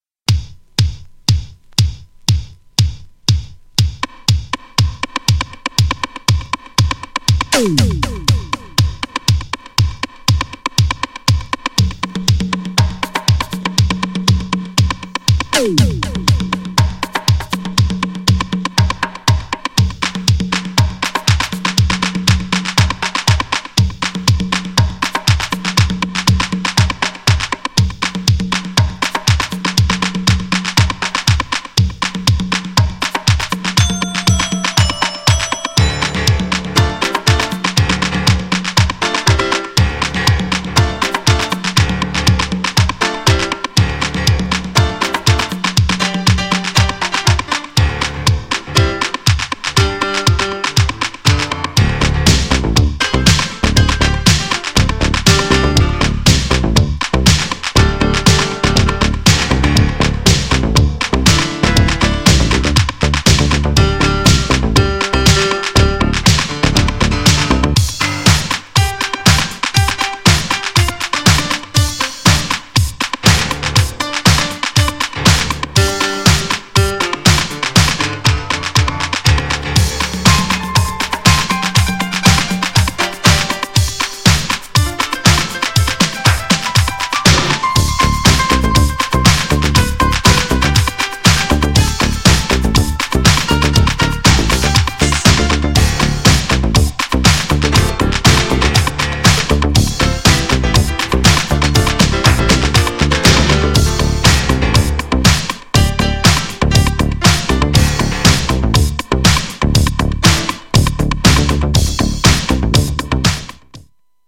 ピアノが絡みまくるDUBはオリジナルバージョンとは違う良さがあります!!
GENRE Dance Classic
BPM 121〜125BPM